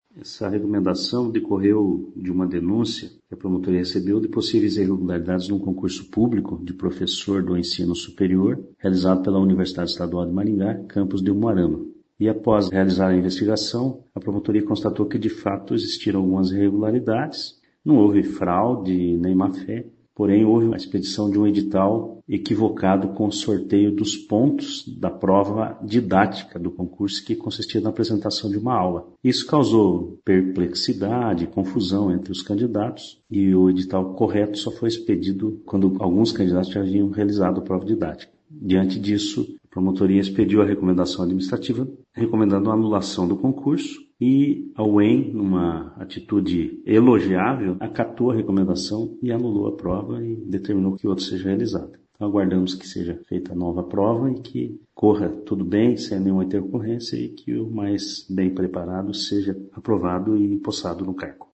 O MP recebeu uma denúncia e constatou uma confusão no sorteio de pontos na prova didática, o que prejudicou alguns candidatos. Não houve má-fé, diz o promotor de Justiça Pedro Ivo Andrade.